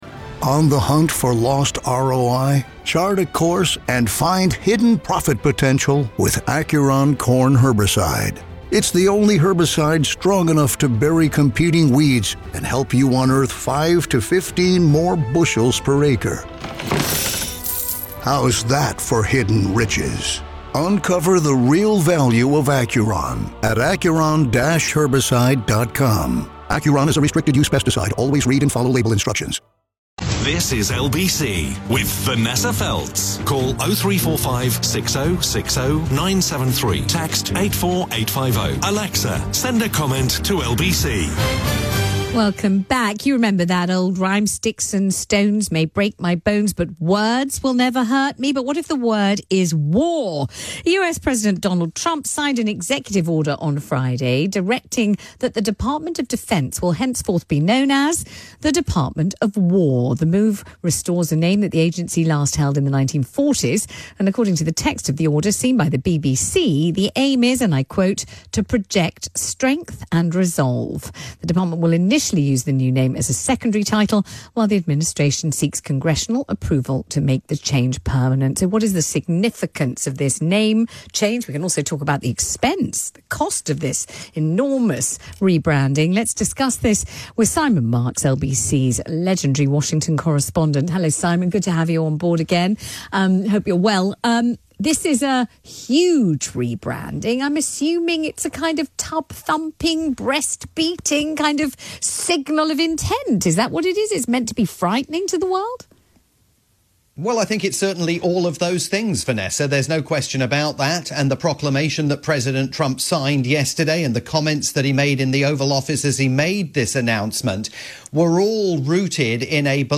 live update for Vanessa Feltz's Saturday afternoon programme on the UK's LBC.